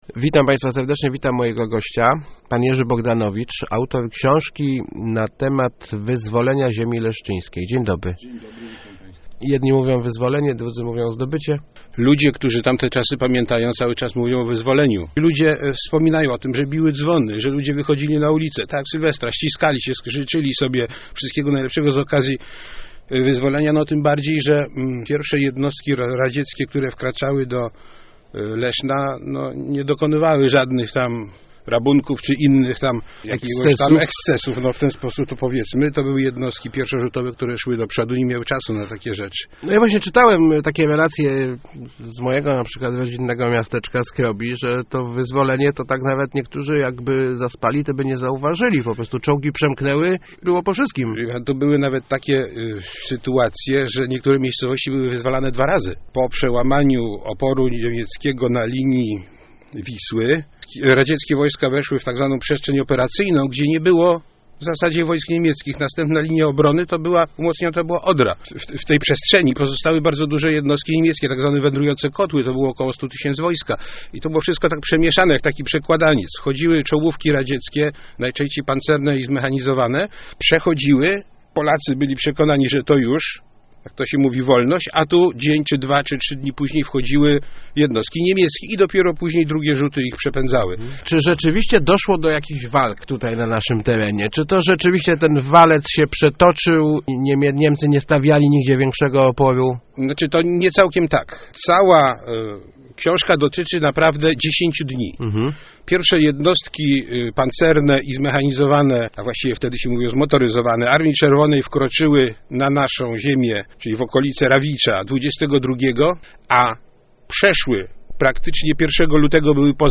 Leszno, Portal Regionu, regionalne, lokalne, radio, elka, Kościan, Gostyń, Góra, Rawicz, Wschowa, żużel, speedway, leszczyński, radio, gazeta, dodatek